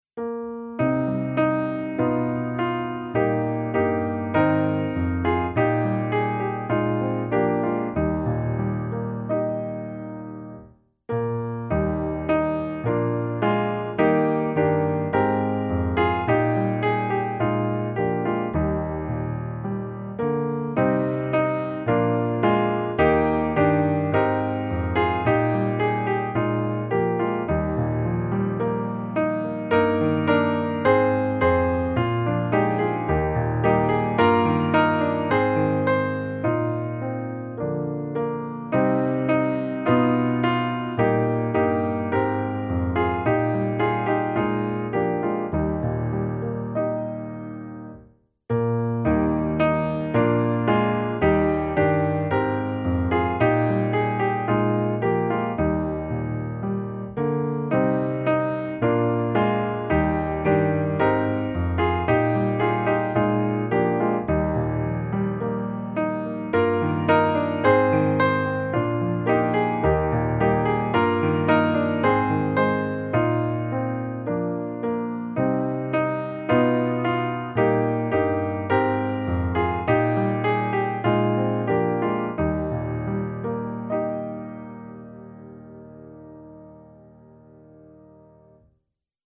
Ett barn är fött på denna dag - musikbakgrund
Musikbakgrund Psalm